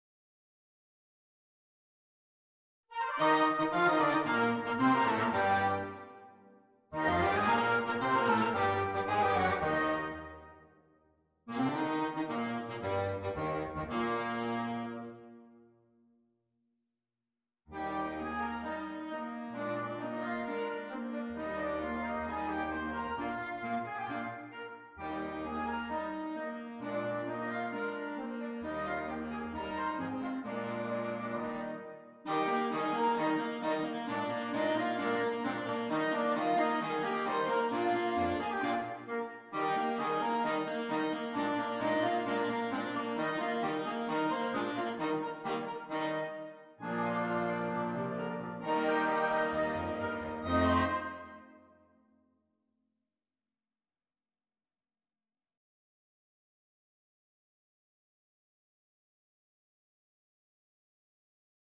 Interchangeable Woodwind Ensemble
This is a fun show-off tune for a mixed woodwind ensemble.